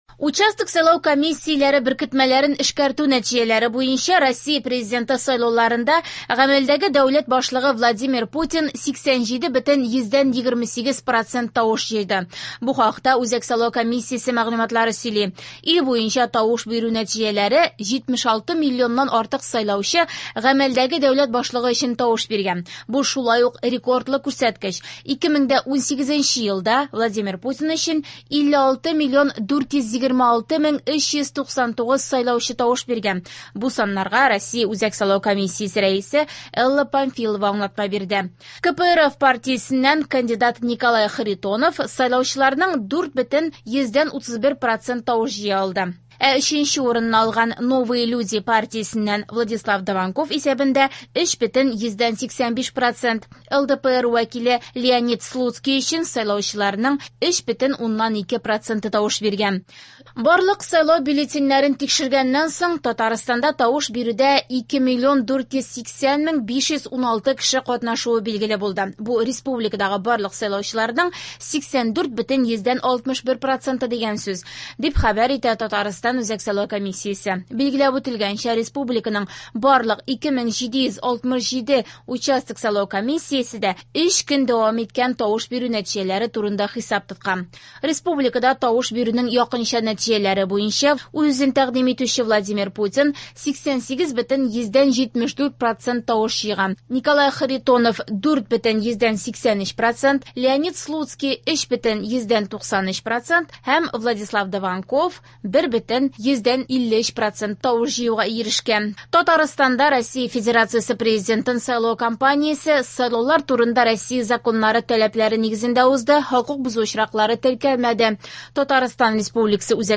Яңалыклар (18.03.24)